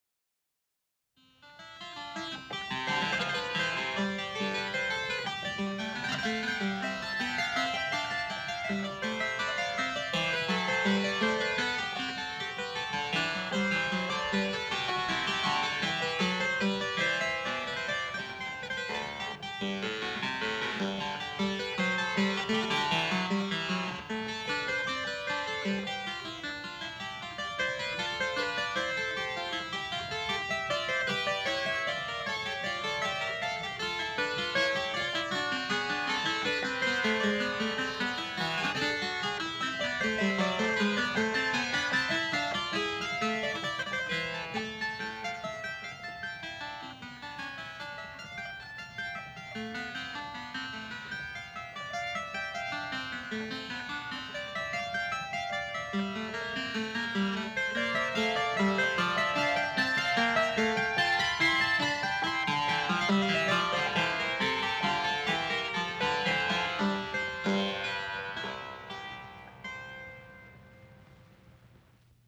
ESCALA MAIOR
Escala Diatônica Maior – produz som mais aberto, expansivo, alegre, extrovertido.